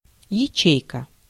Ääntäminen
France: IPA: /se.lyl/